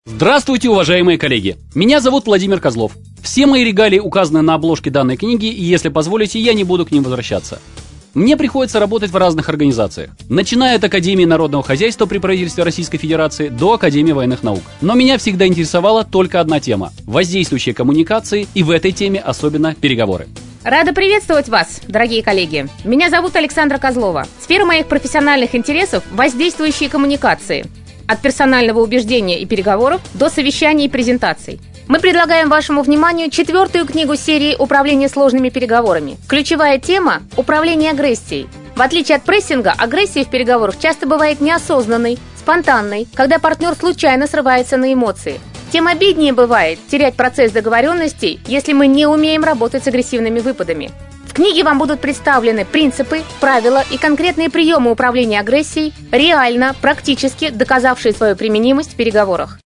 Аудиокнига Техники управления агрессией | Библиотека аудиокниг